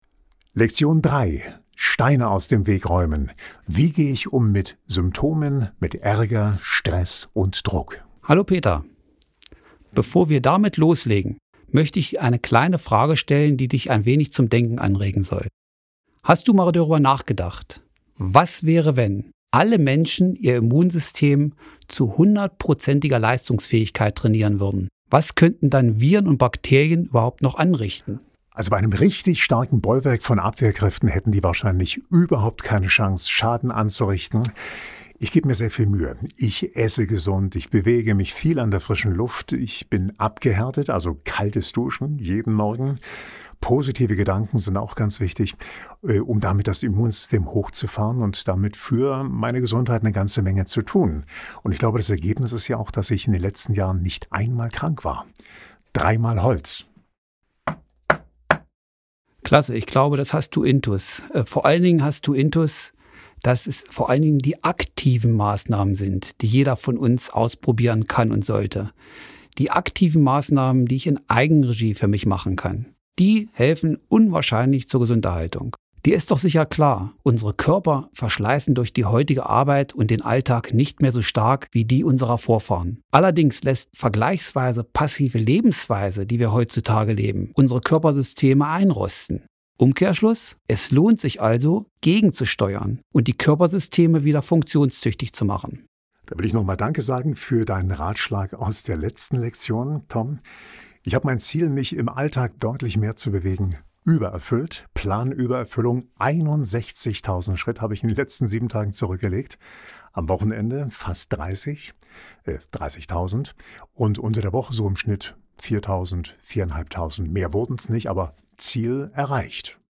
Dialog